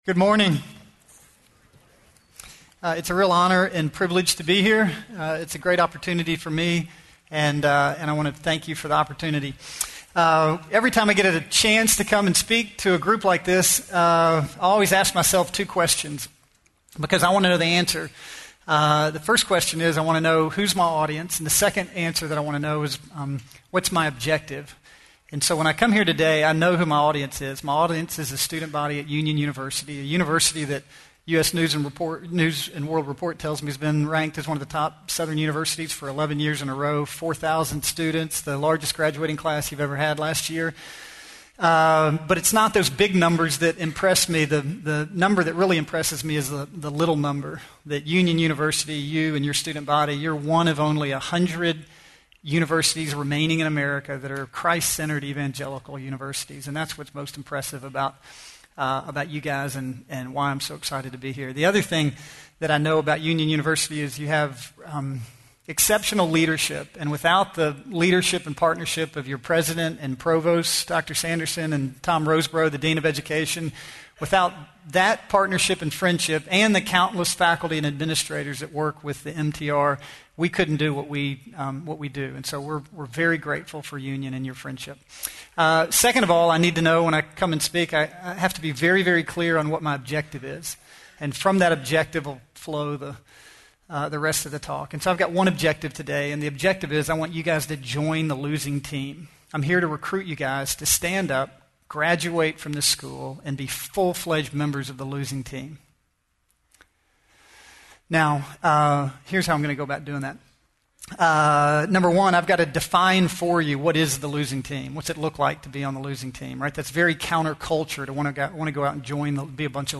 Union University, a Christian College in Tennessee
Chapels